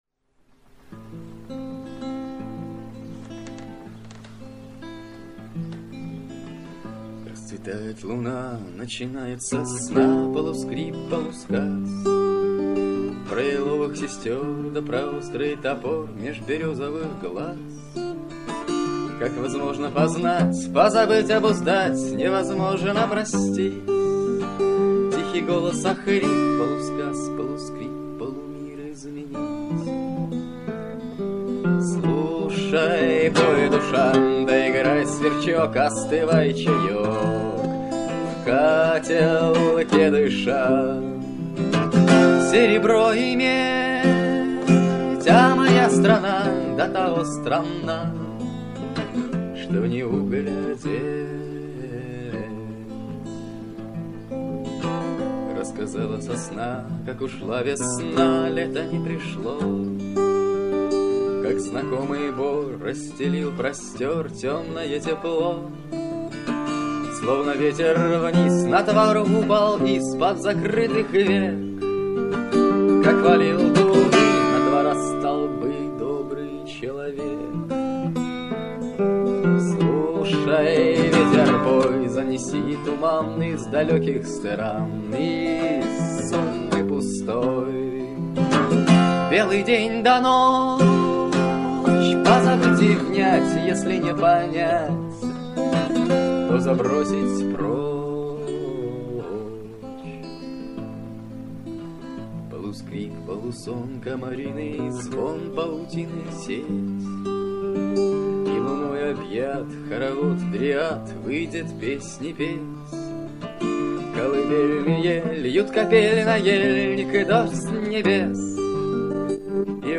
На одной старой кассете в мамином архиве нашёлся целый комплект всякого добра из 1995 года.